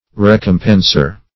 Search Result for " recompenser" : The Collaborative International Dictionary of English v.0.48: Recompenser \Rec"om*pen`ser\ (-p?n`s?r), n. One who recompenses.